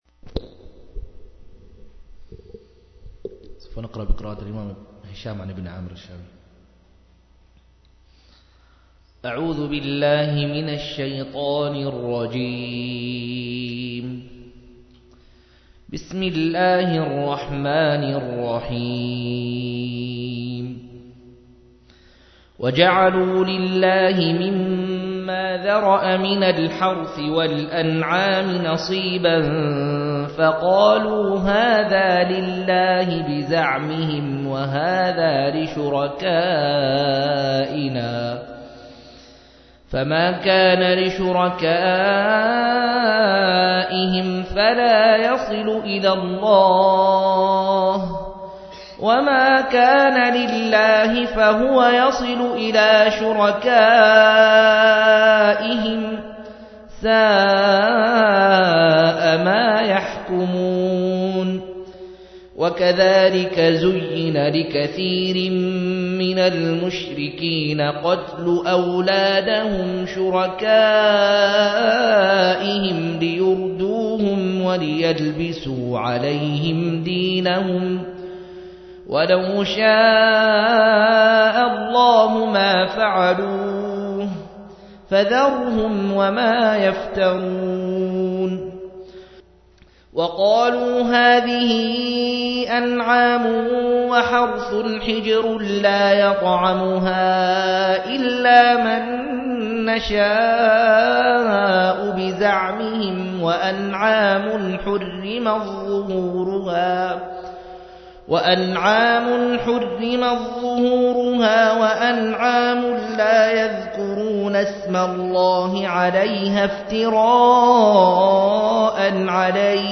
139- عمدة التفسير عن الحافظ ابن كثير رحمه الله للعلامة أحمد شاكر رحمه الله – قراءة وتعليق –